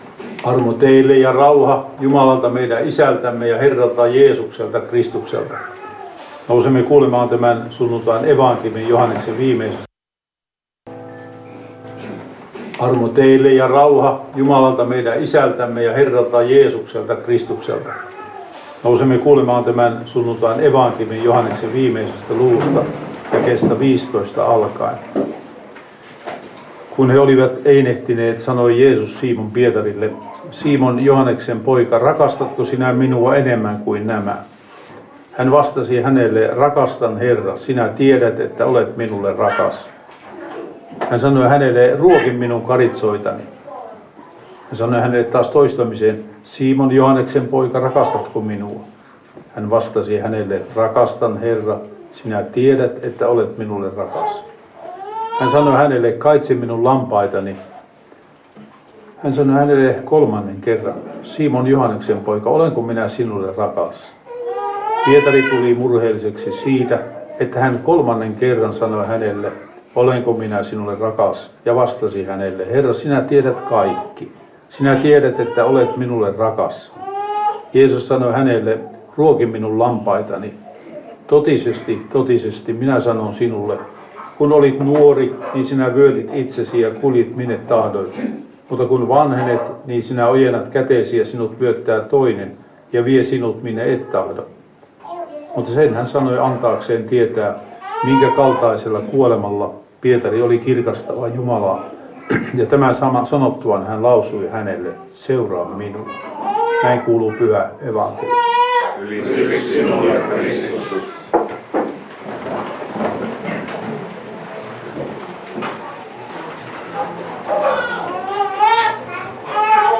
saarna Lappeenrannassa 2. sunnuntaina pääsiäisestä Tekstinä Joh. 21:15–19